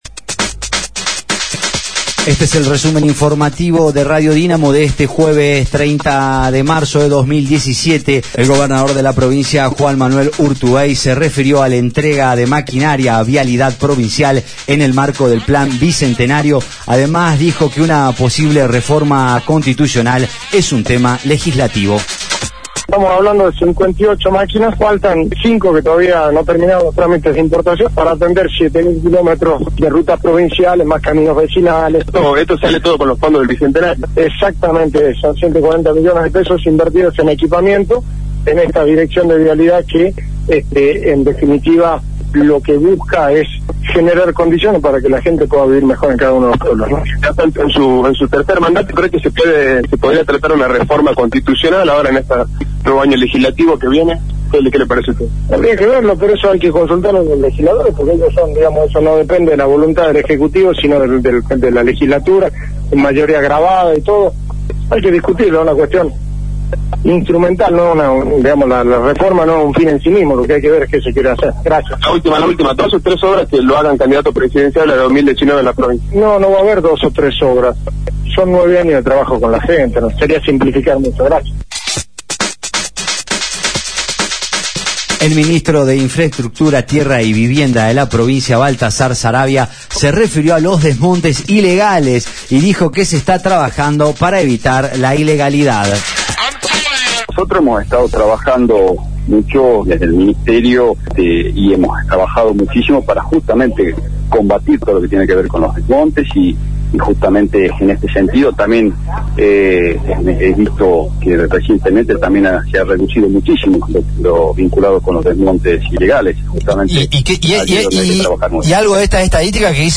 Resumen Informativo de Radio Dinamo del día 30/03/2017 2° Edición